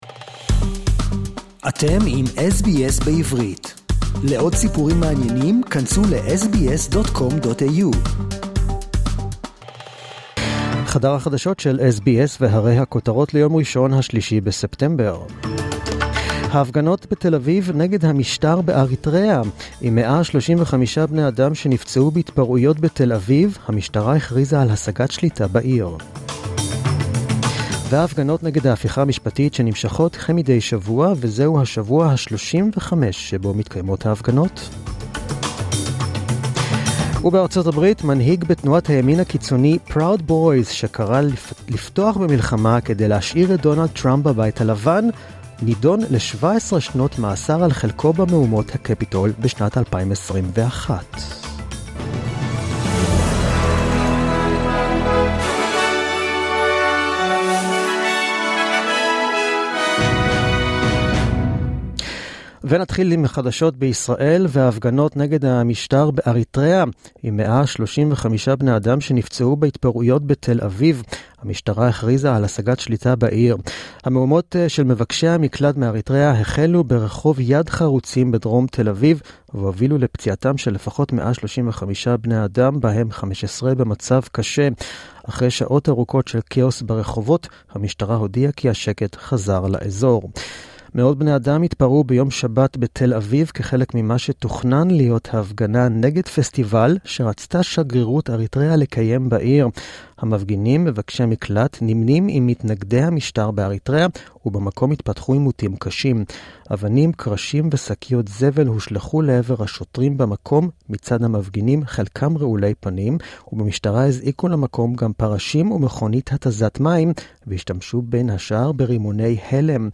The News in Hebrew (3.9.23)
The latest news in Hebrew, as heard on the SBS Hebrew program